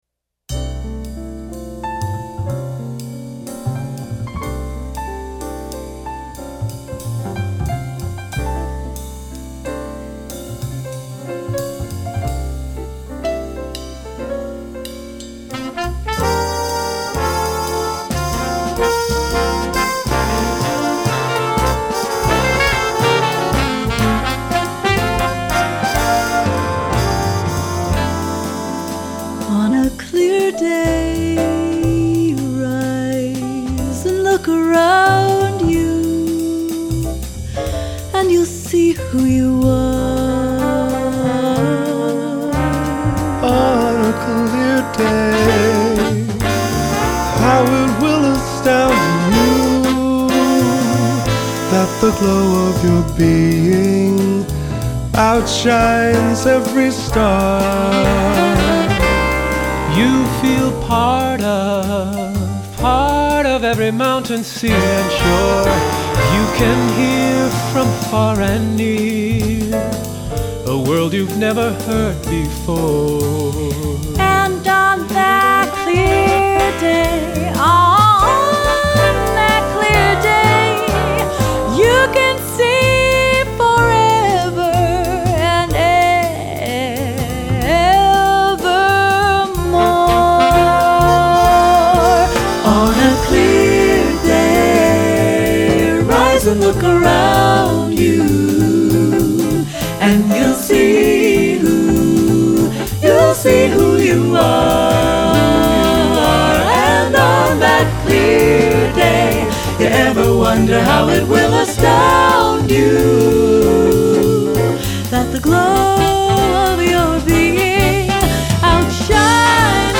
Voicing: SATB w/BB